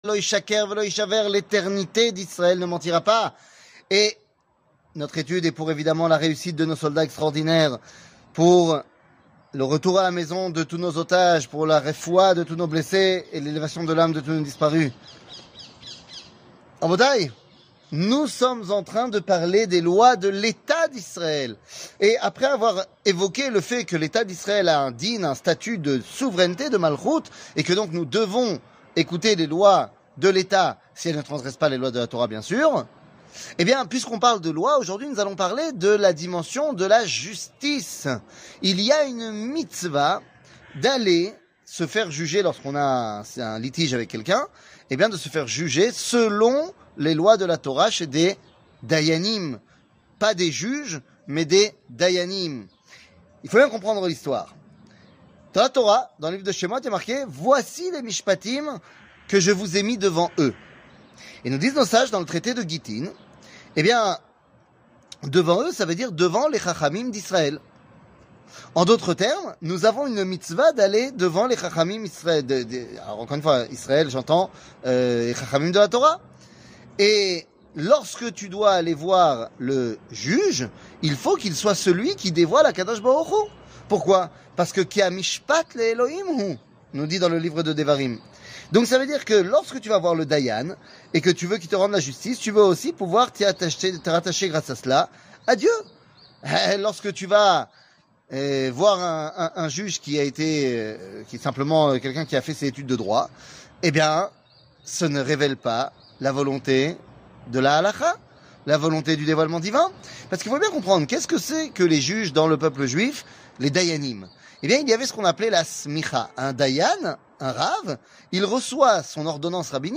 שיעורים קצרים